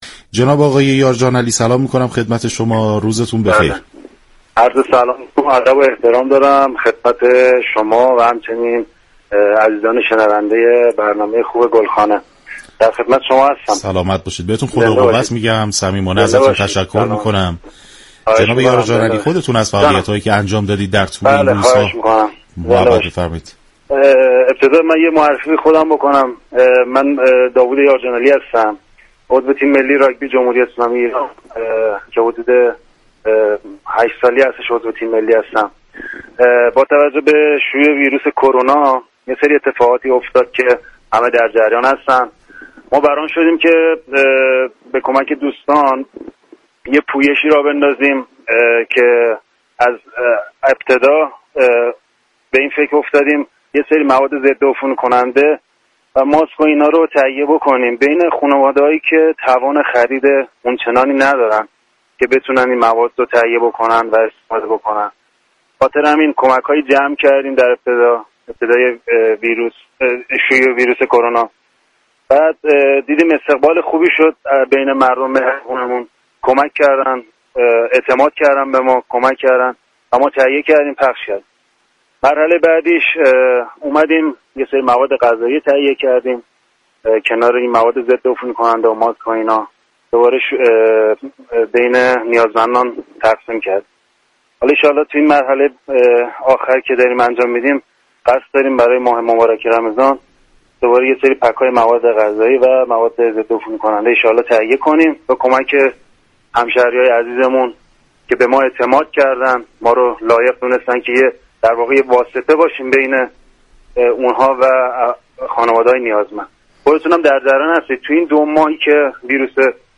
شما می توانید از طریق فایل صوتی پیوست شنونده بخشی از برنامه "گلخونه" رادیو ورزش كه به توضیح درباره نحوه فعالیت های این ورزشكار در پویش همدلی و مساوات از زبان خودش می پردازد؛ باشید.